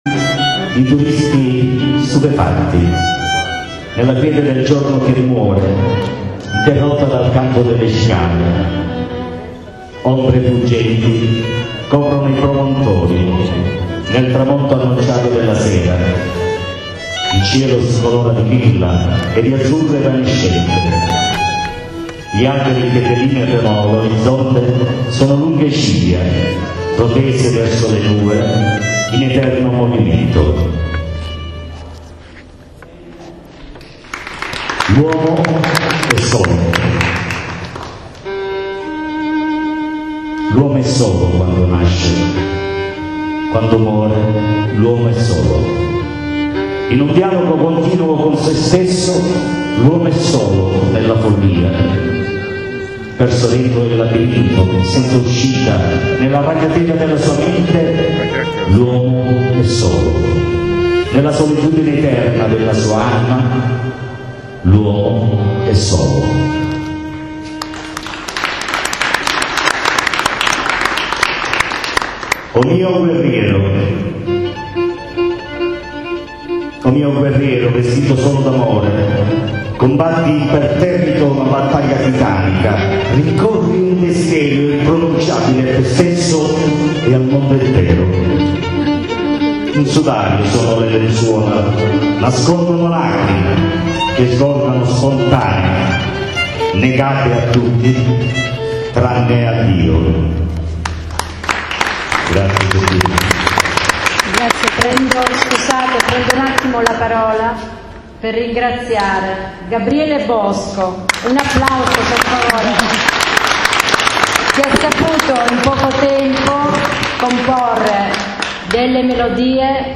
Rassegna letteraria a Noto Tra poesia dialettale e musica popolare